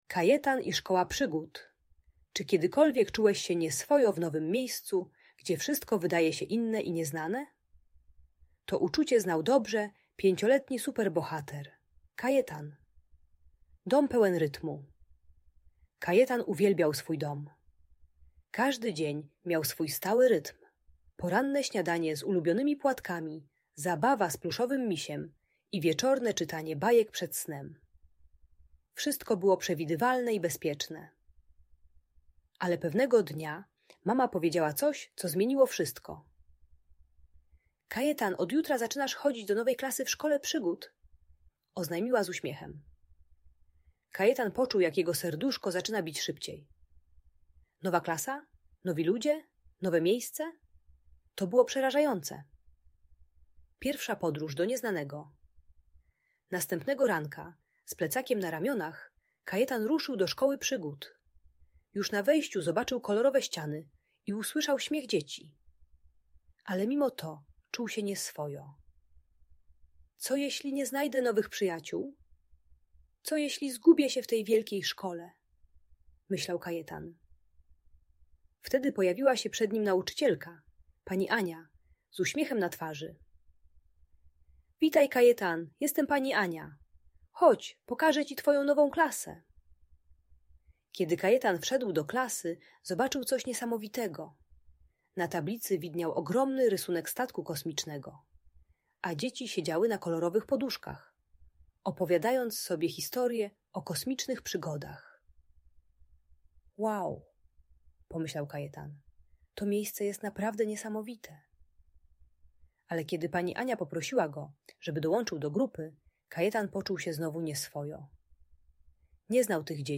Kajetan i Szkoła Przygód - historia o odwadze i przyjaźni - Audiobajka